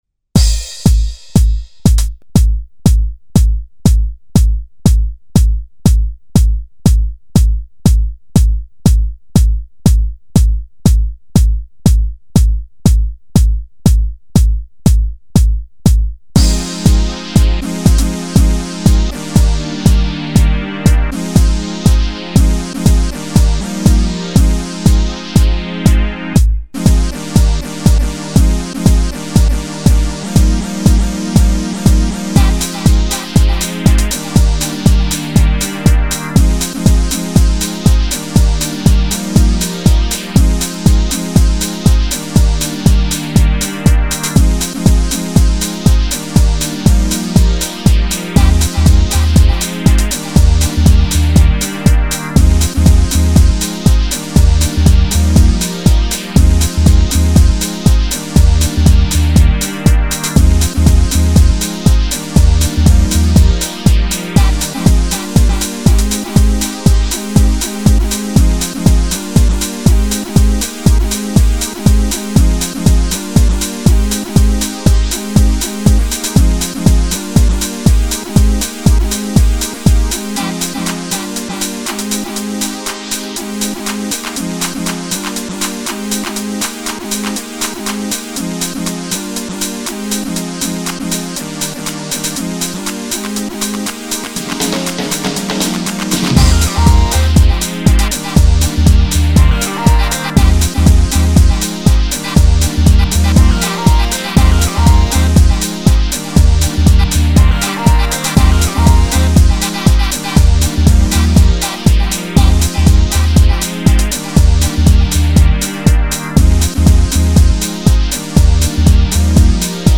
Style :pop/cut up